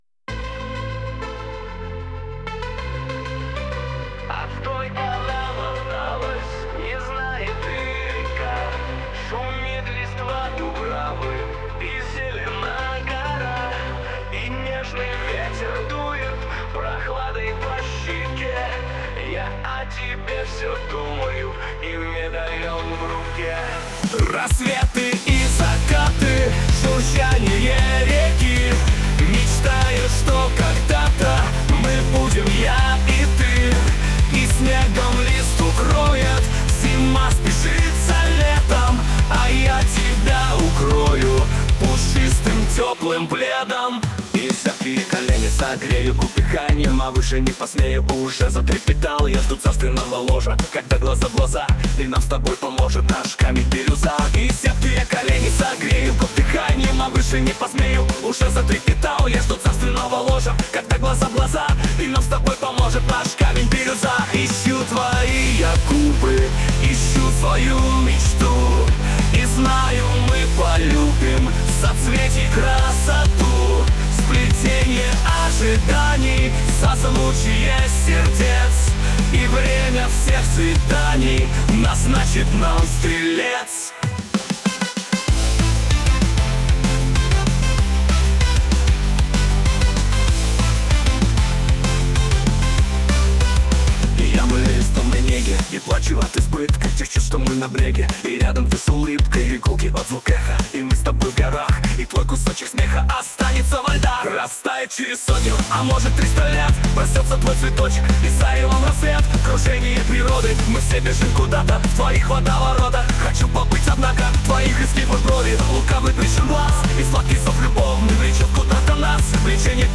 Песня написана на любовные стихи 2013 года